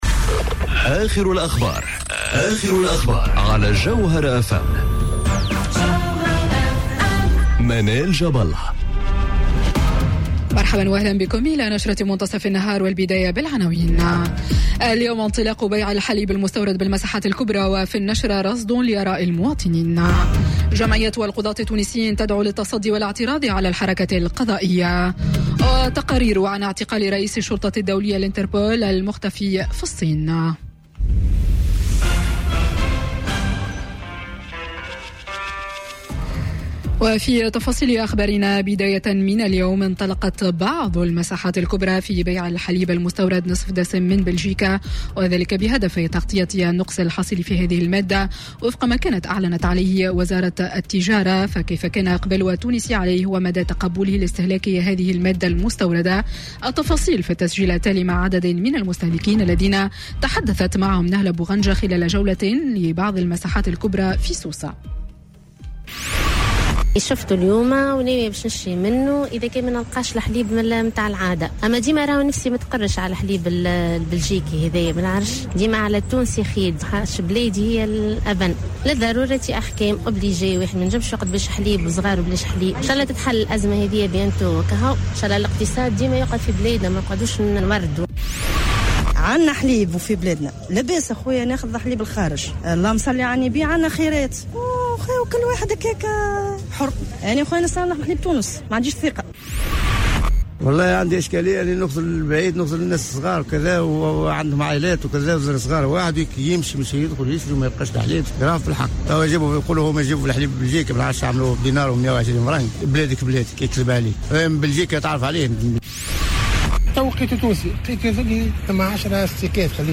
نشرة أخبار منتصف النهار ليوم السبت 06 أكتوبر 2018